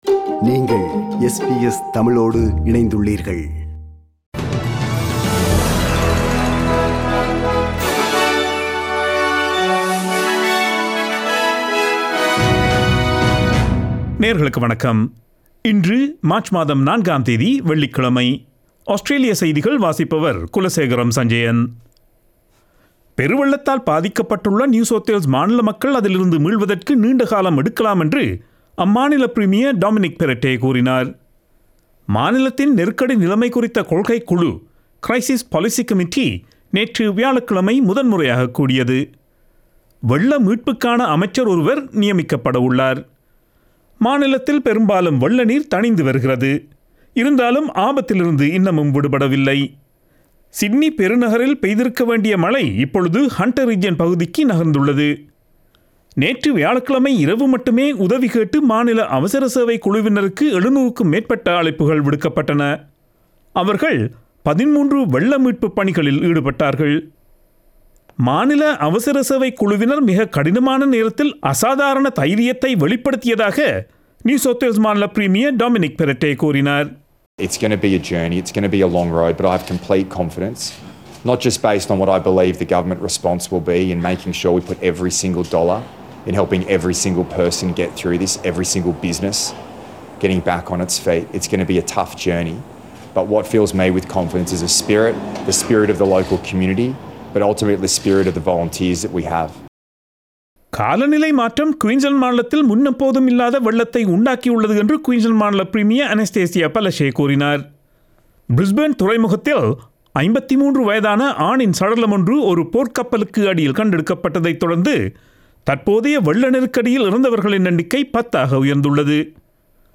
Australian news bulletin for Friday 04 March 2022.